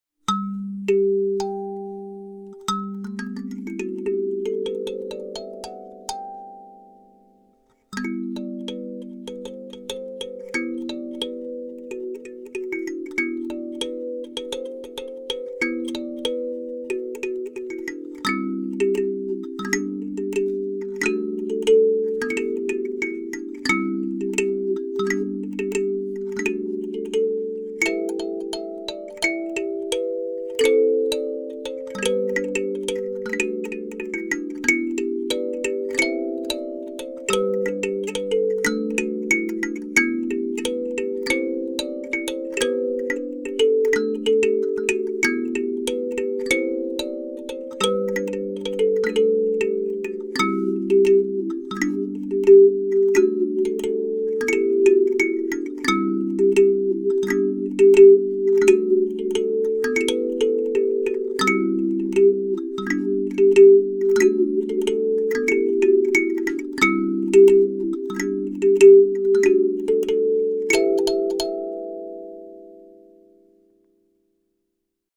Its easy to play and understand, has a sweet sound, and can play a very wide range of music: pop, folk, rock, African, classical, carols.
Made from kiaat wood and high quality spring steel, this is a real musical instrument and is easy to play, not just a pretty coffee table ornament.
Alto Kalimba, standard G tuning